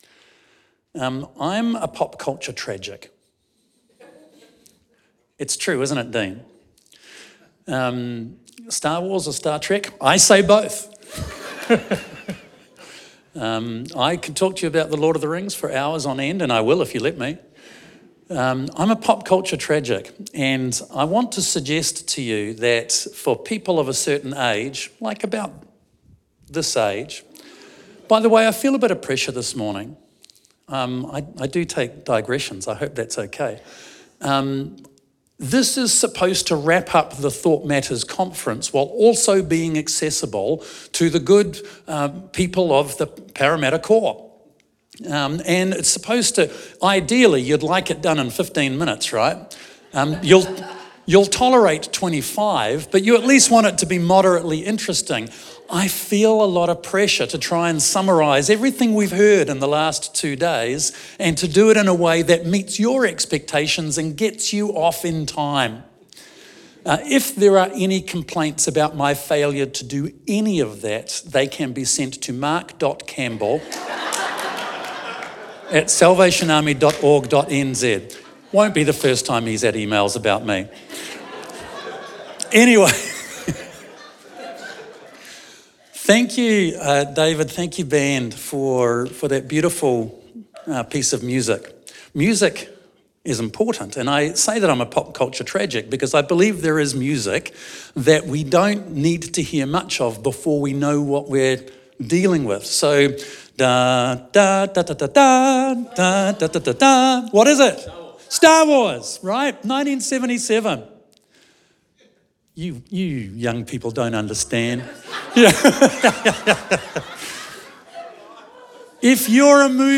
The Thought Matters Conference was held at Parramatta from 1st to the 3rd November.